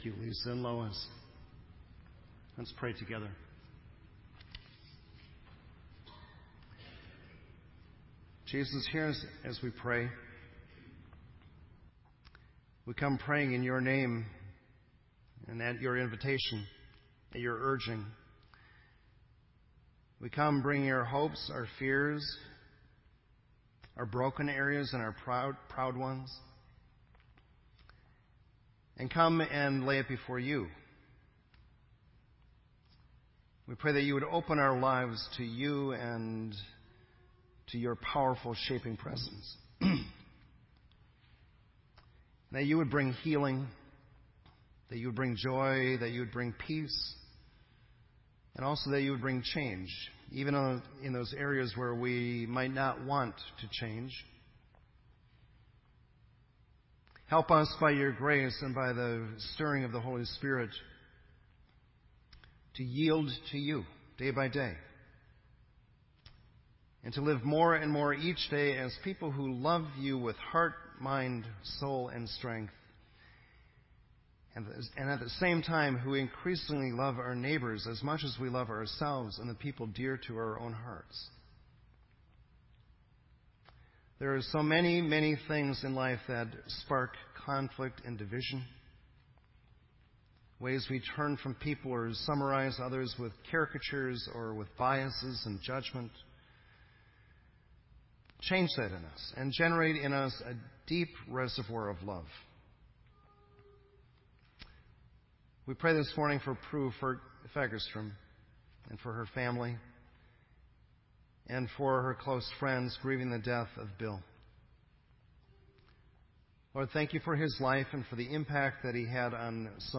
This entry was posted in Sermon Audio on October 19